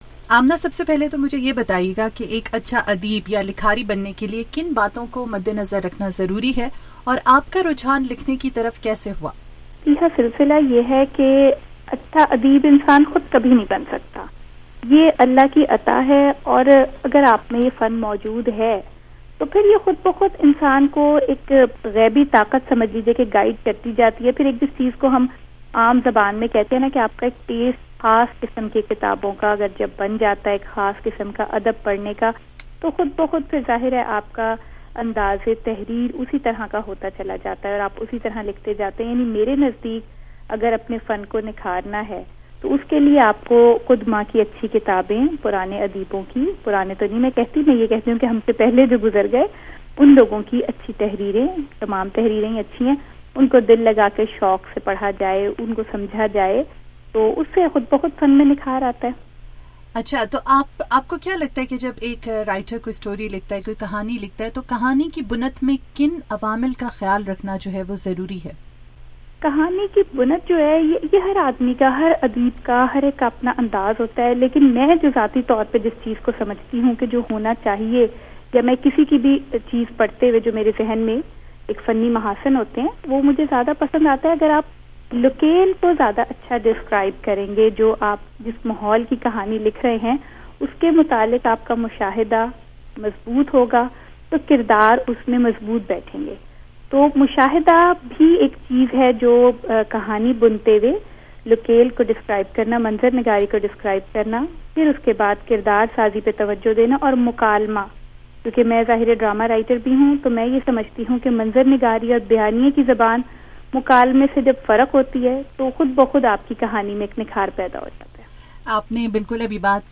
’الّو برائے فروخت نہیں‘ کی رائٹر آمنہ مفتی سے خصوصی گفتگو